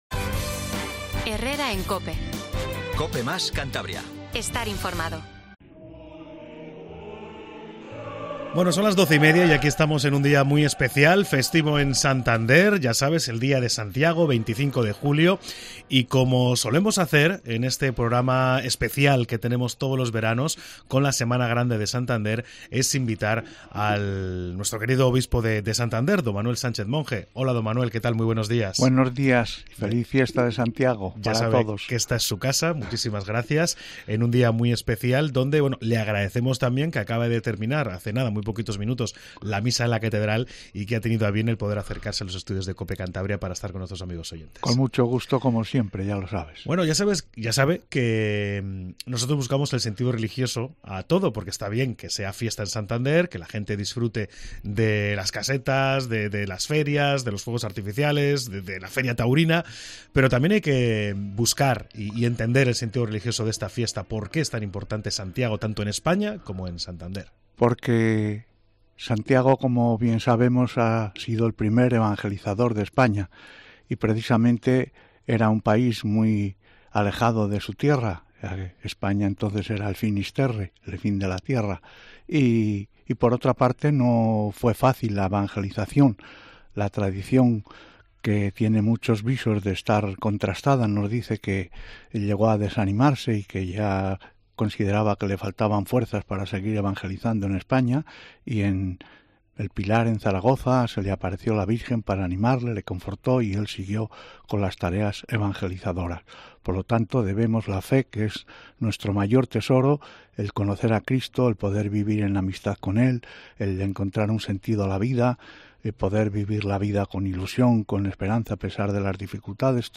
AUDIO: Escucha al obispo de Santander, Don Manuel Sánchez Monge, ensalzar en Cope Cantabria a Santiago Apóstol